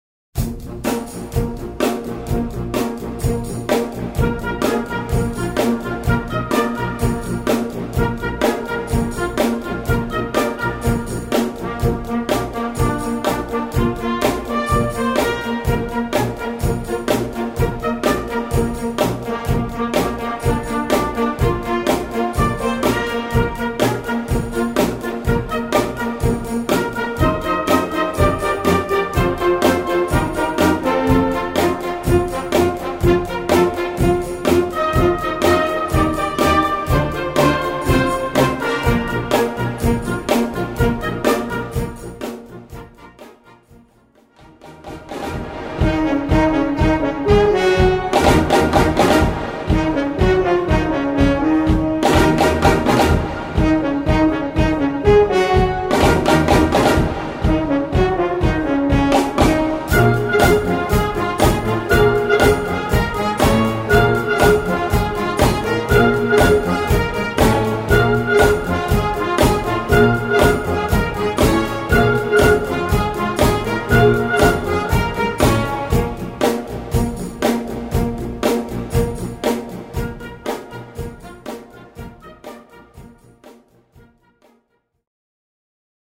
Recueil pour Orchestre - Orchestre Juniors